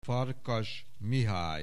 Aussprache Aussprache
FARKASMIHALY.wav